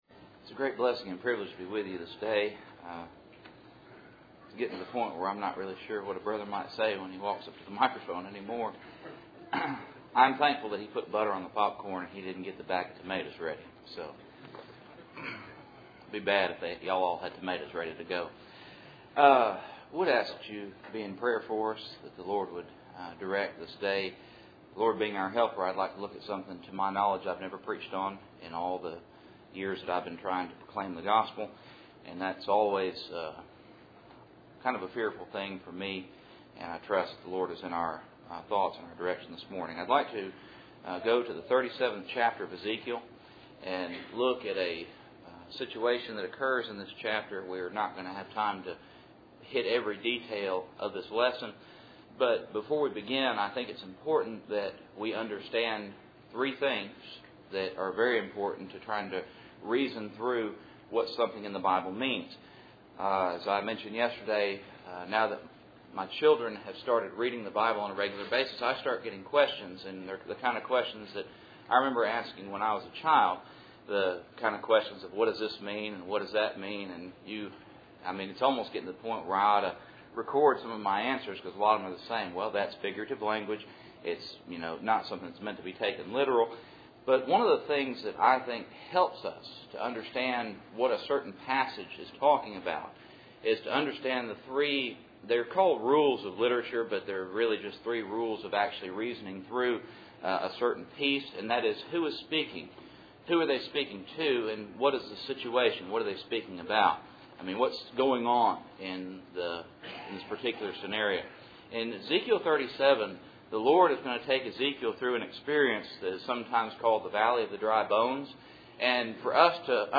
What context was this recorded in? Cool Springs PBC Sunday Morning %todo_render% « What Went Ye Out to See?